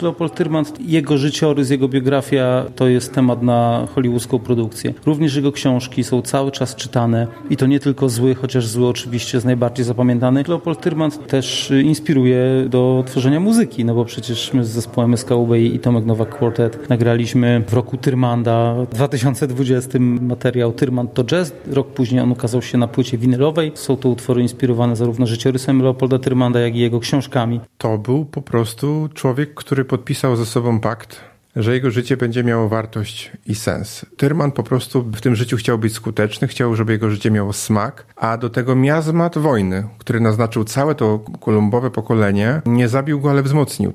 Spotkanie z biografem Leopolda Tyrmanda